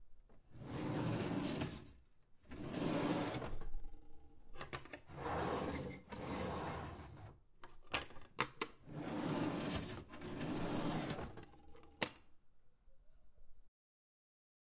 抽屉被打开和关闭
描述：我们厨房的抽屉在打开和关闭时会发出巨大的噪音。
Tag: 开口 打开 OWI 抽屉